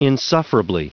Prononciation du mot insufferably en anglais (fichier audio)
Prononciation du mot : insufferably